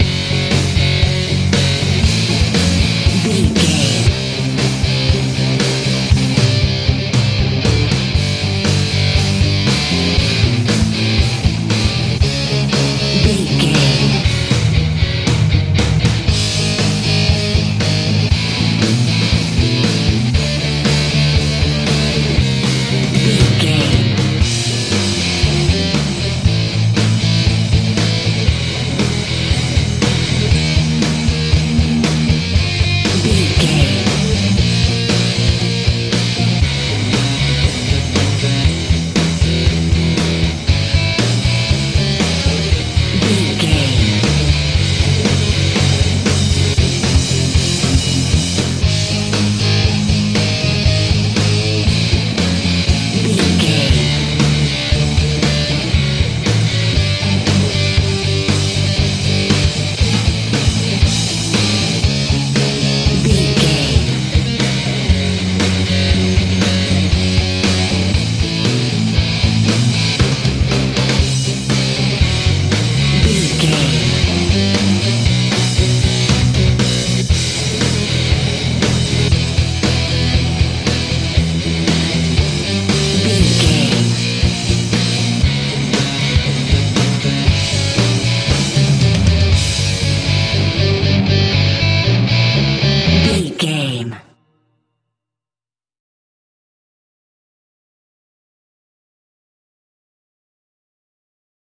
nu mental med rock feel
Ionian/Major
F♯
disturbing
confused
electric guitar
bass guitar
drums
powerful
aggressive